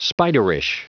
Prononciation du mot spiderish en anglais (fichier audio)
Prononciation du mot : spiderish